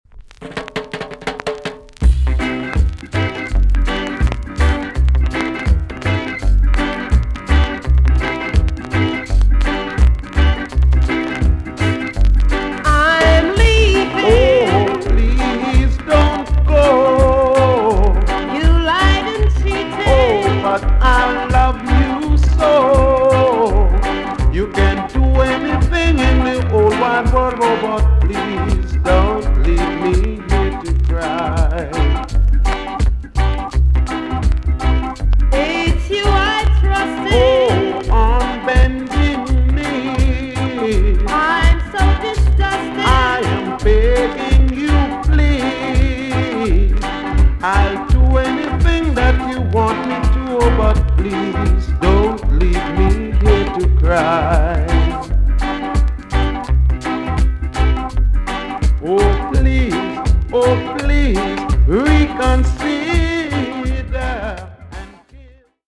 VG+~VG ok 軽いチリノイズがあります。
ROCK STEADY REMAKE TUNE!!